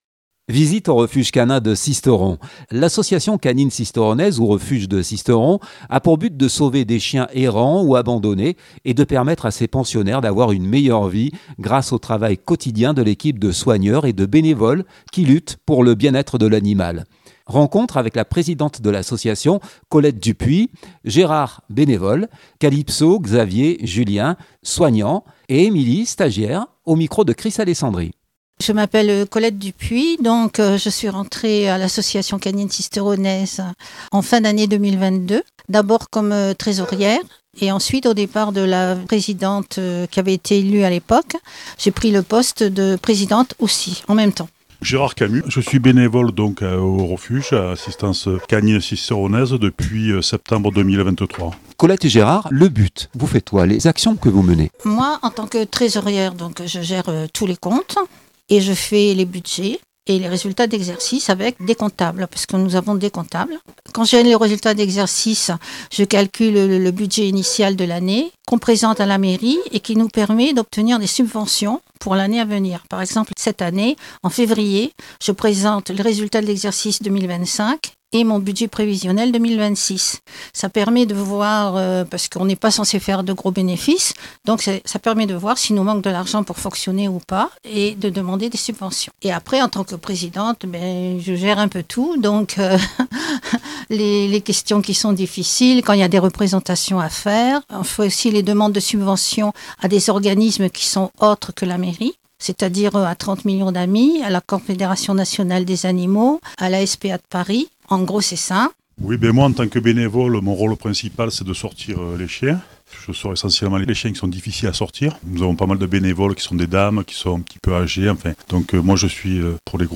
Visite au Refuge Canin de Sisteron